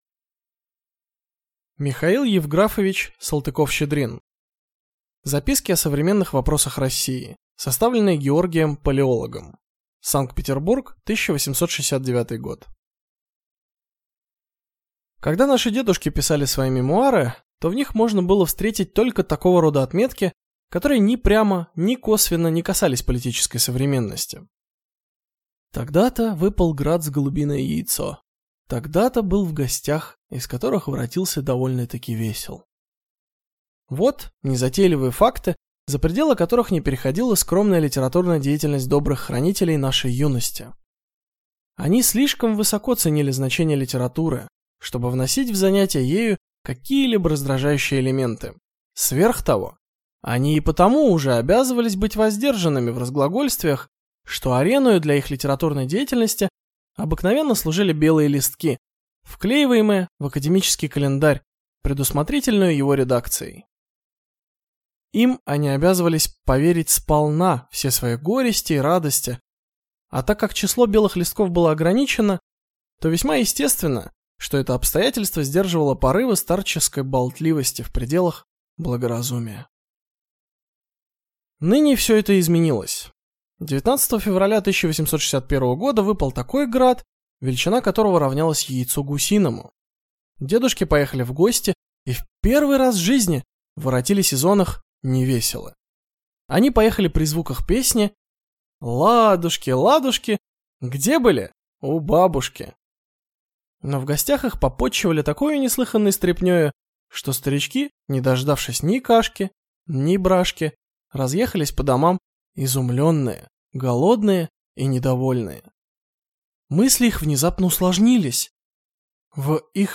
Aудиокнига Записки о современных вопросах России Автор Михаил Салтыков-Щедрин